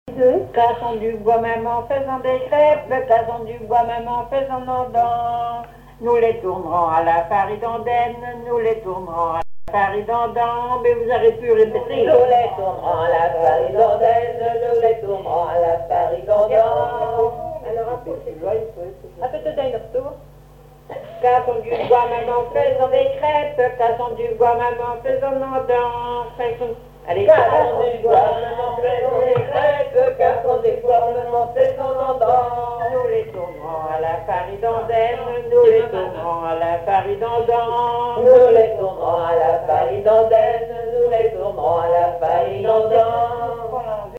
Couplets à danser
danse : branle
collecte en Vendée
chansons traditionnelles et commentaires